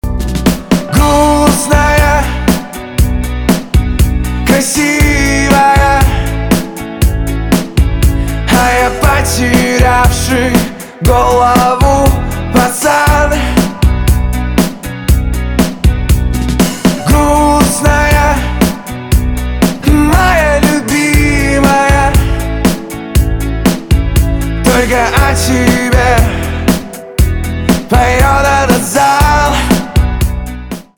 русский рок , гитара , барабаны , чувственные , пианино